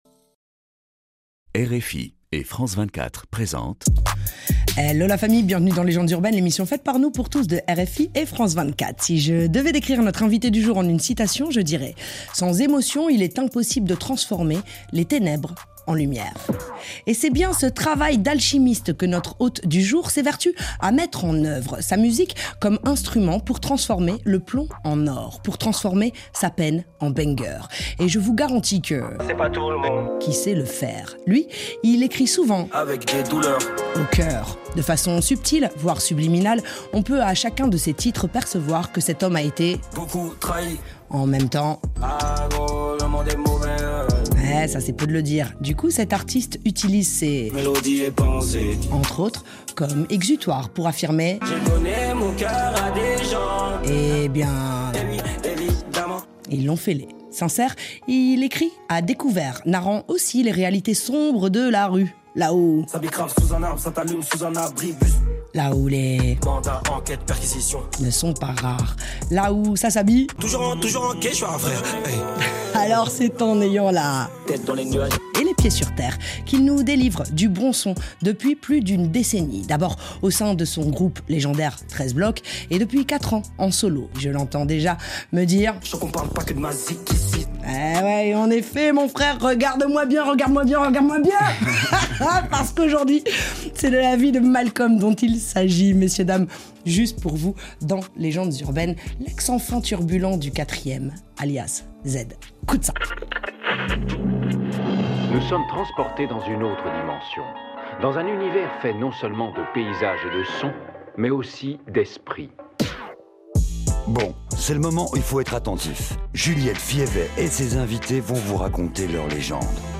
SDM, Solide comme un roc. Spéciale sélection musicale légendaire.